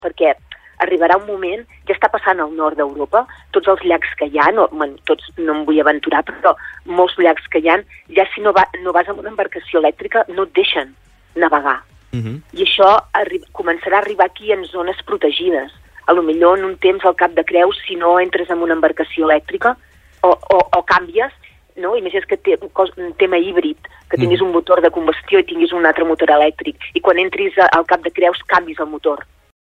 Entrevistes SupermatíForallac